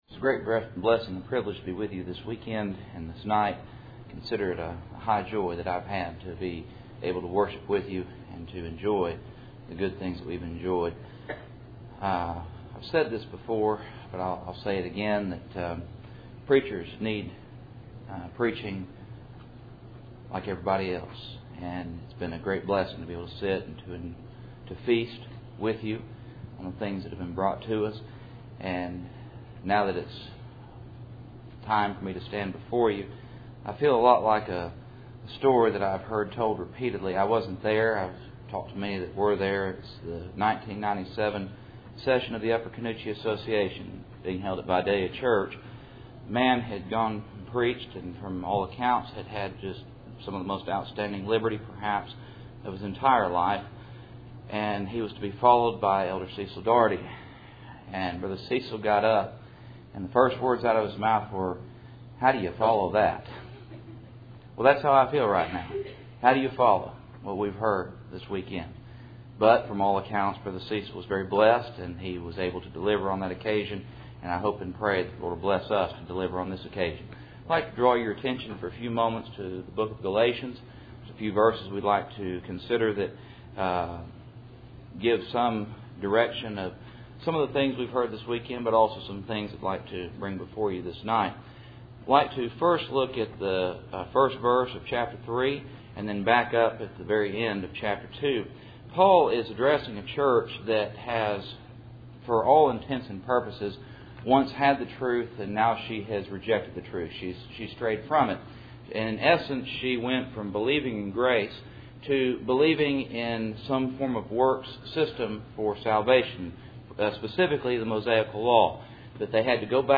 Passage: Galatians 2:20-21 Service Type: Cool Springs PBC Sunday Evening %todo_render% « Representation